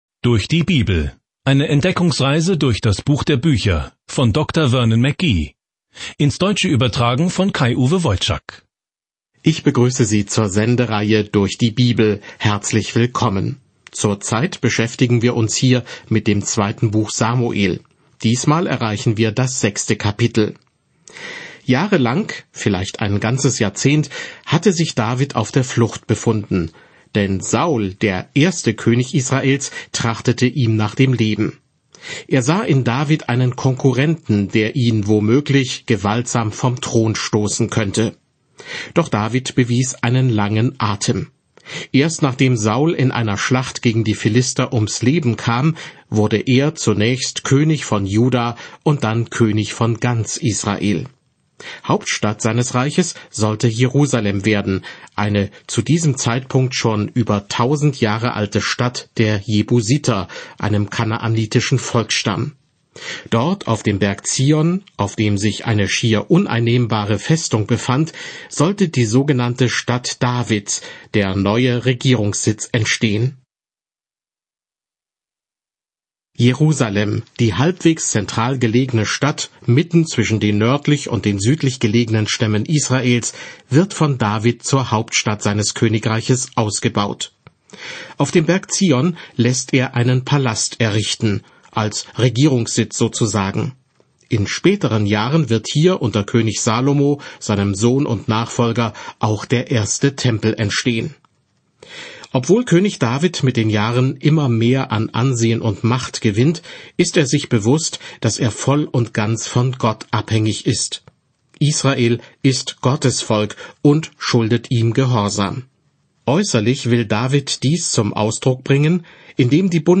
Samuel 6 Tag 2 Diesen Leseplan beginnen Tag 4 Über diesen Leseplan Die Geschichte der Beziehung Israels zu Gott wird mit der Einführung von Propheten in die Liste der Beziehungen zwischen Gott und seinem Volk fortgesetzt. Reisen Sie täglich durch 2. Samuel, während Sie sich die Audiostudie anhören und ausgewählte Verse aus Gottes Wort lesen.